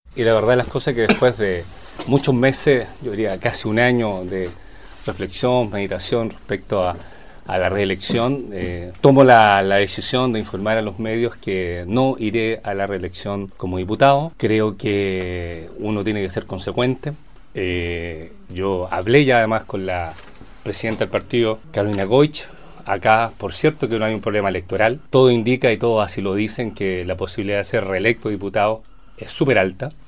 El legislador, hasta ahora representante del Distrito 57, dio a conocer su determinación este lunes en conferencia de prensa en Puerto Montt, reconociendo que ya había informado de ello a la presidenta de su partido, la Democracia Cristiana, senadora Carolina Goic.